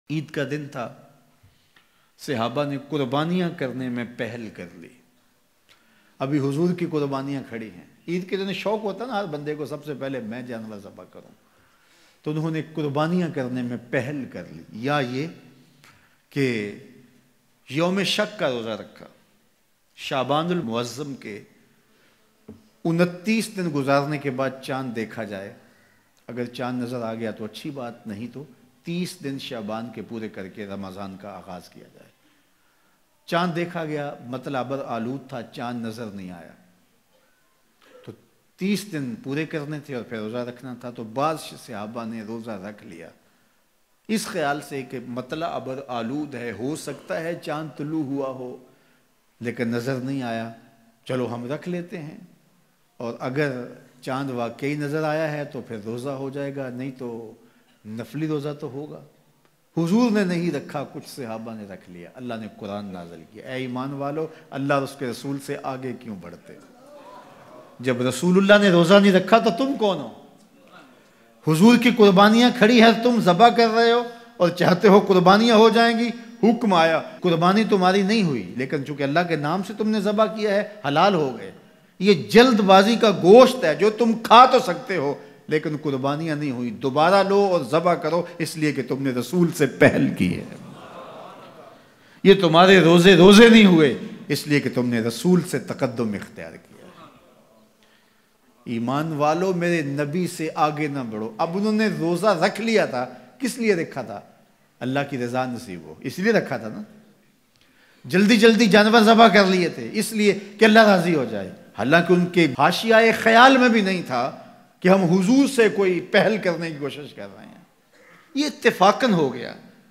Hazrat USMAN ne Twaf kio na kiya Bayan MP3